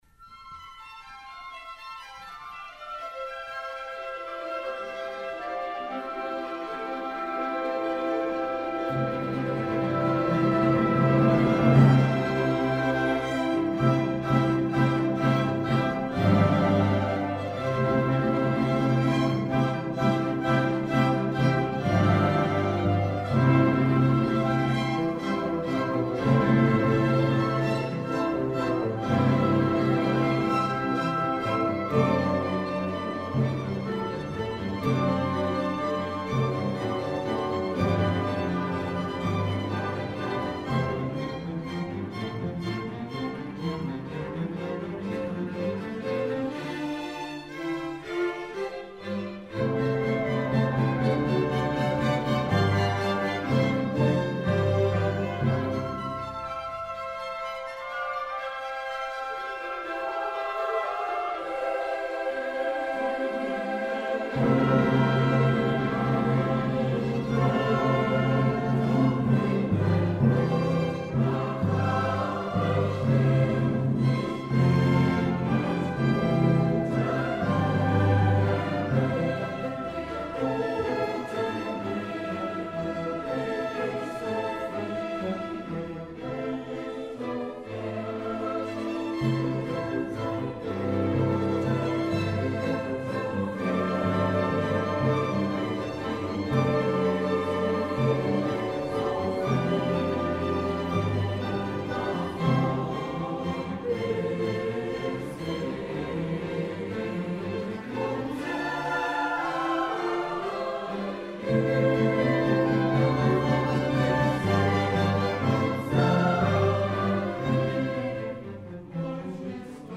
Orchester des Sinfonischen Musikvereins Leipzig e. V.
T.K. Weihnachtskonzert im "Gewandhaus zu Leipzig" im Mendelssohnsaal am Do. 08.12.2022 20:00 Uhr Aus dem Programm Felix Mendelssohn Bartholdy: Verleih uns Frieden (MWV A 11) Antonín Dvořák: Tschechische Suite op. 39 1.
Vom Himmel hoch - Choralkantate
Sopran
Bariton
vom_himmel_hoch_choralkantate_T2.mp3